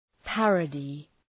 Προφορά
{‘pærədı}